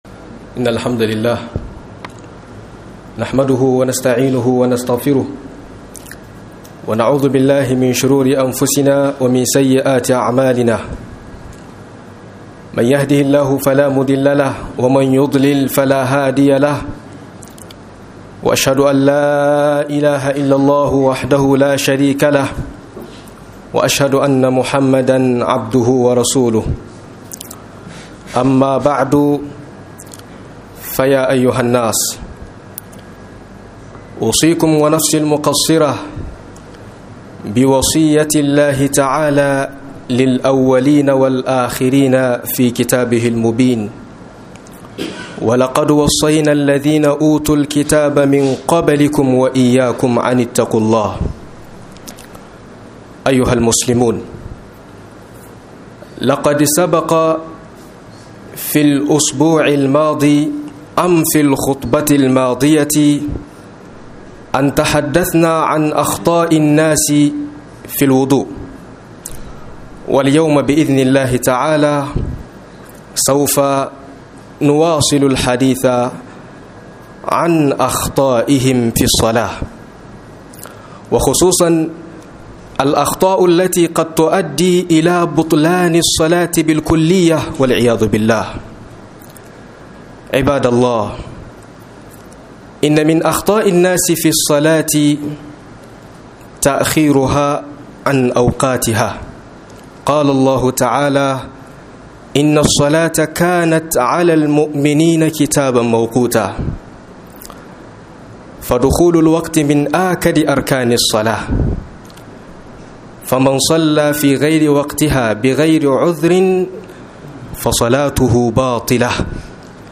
02 KURAKURAN MUTANE ACIKN SALLAH - MUHADARA